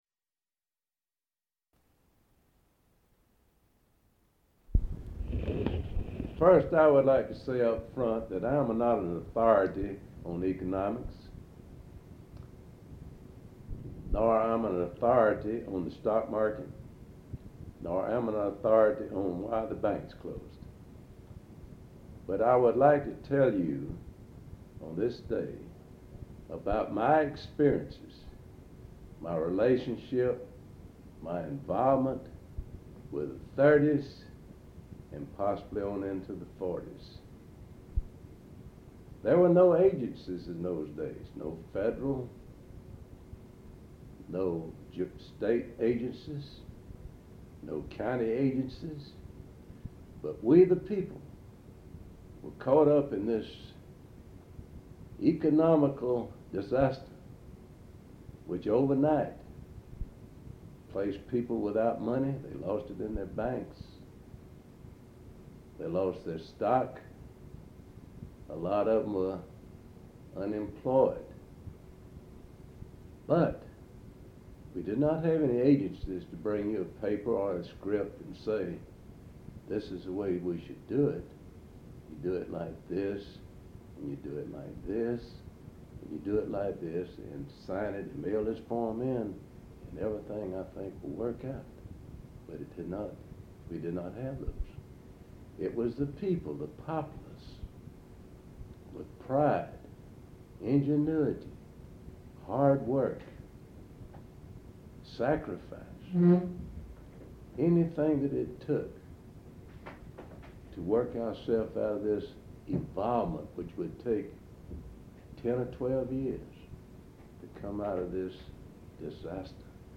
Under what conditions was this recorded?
Valdosta, Lowndes County, Georgia. Audio file digitized from cassette tape.